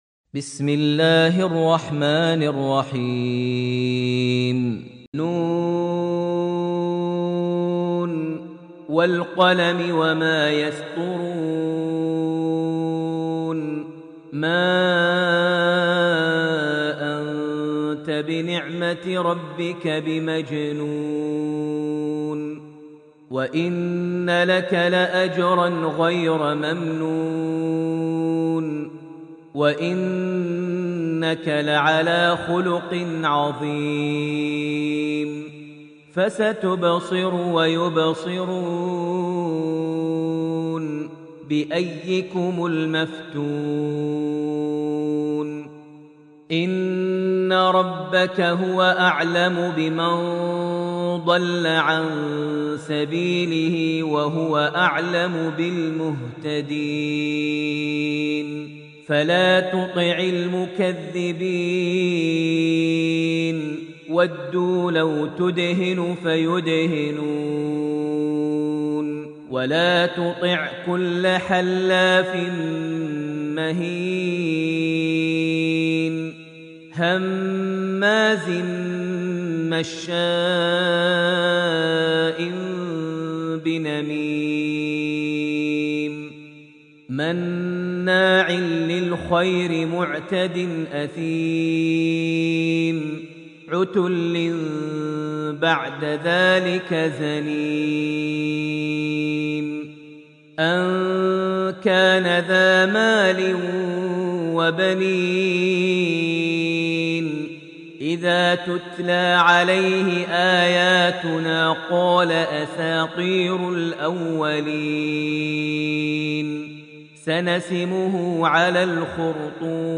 Surah Al-Qalam > Almushaf > Mushaf - Maher Almuaiqly Recitations